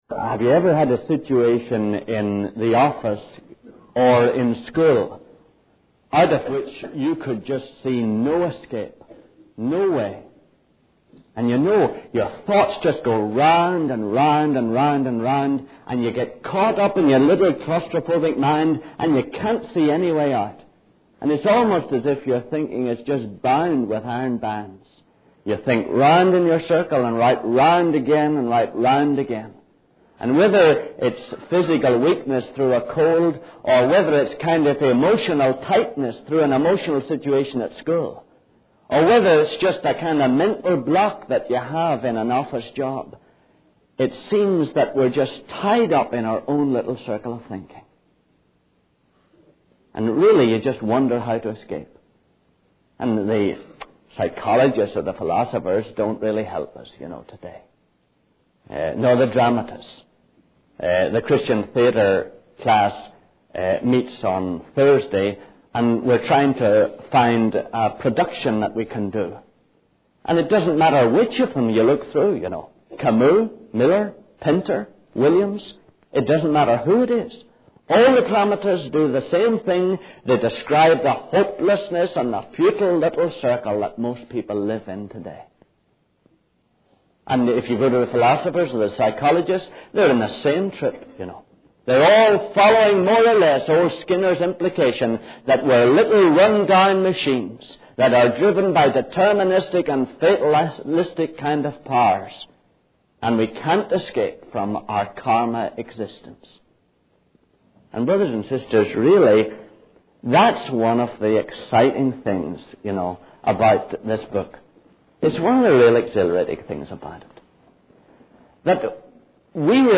In this sermon, the speaker emphasizes the need for real spiritual nourishment in a world filled with distractions.